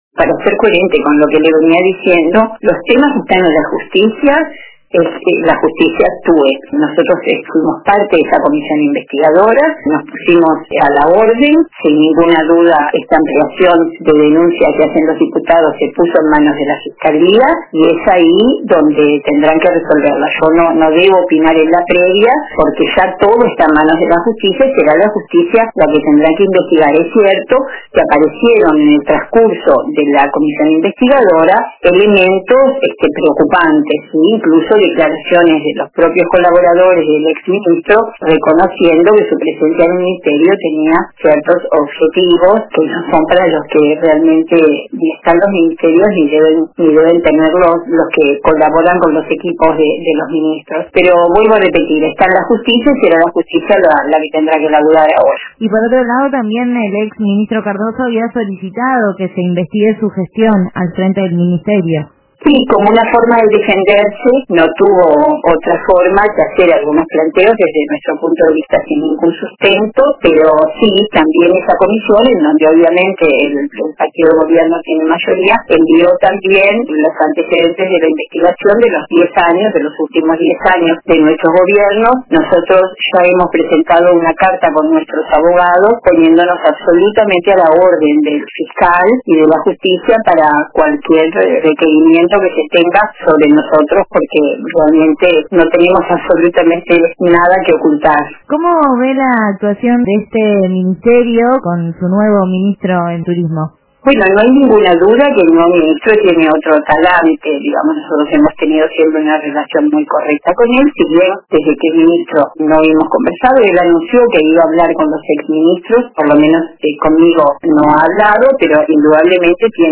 La senadora frenteamplista y ex ministra de Turismo, Liliam Kechichian, participó este viernes en LA TARDE DE RBC